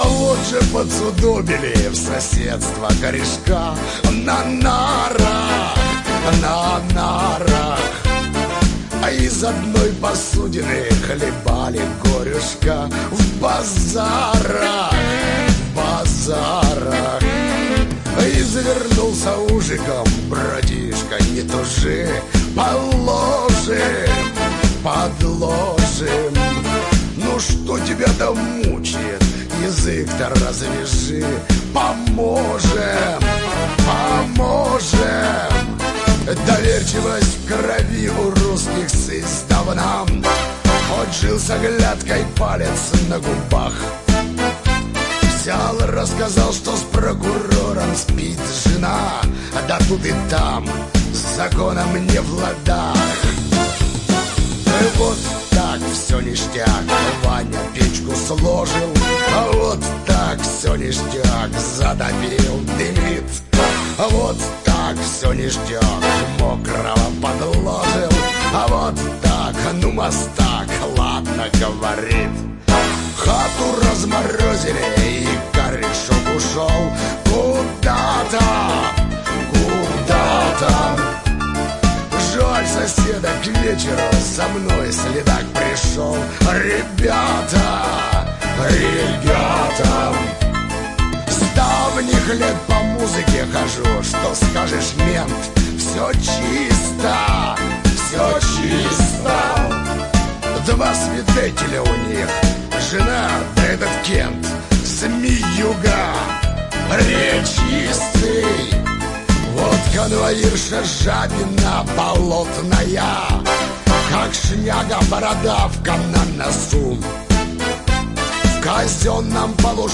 Классический блатной шансон